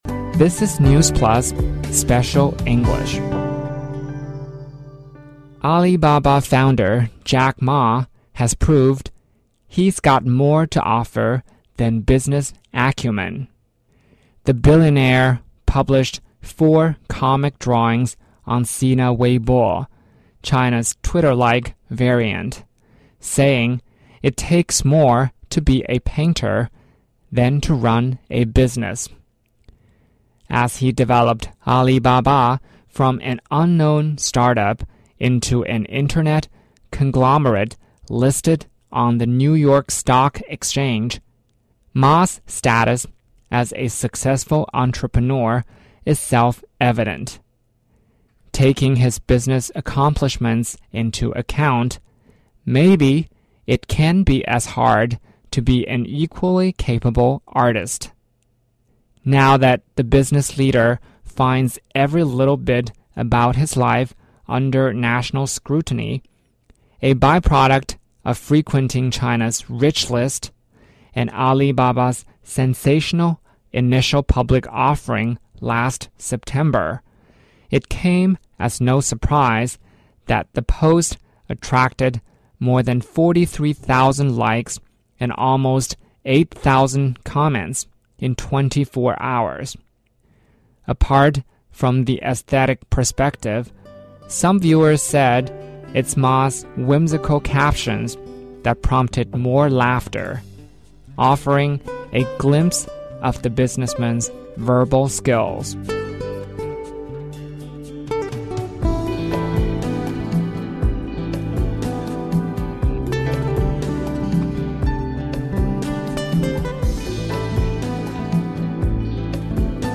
News Plus慢速英语:马云微博画连环画玩自黑 我国首次在北方高纬度地区饲养大熊猫